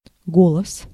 Ääntäminen
US : IPA : [wɝd]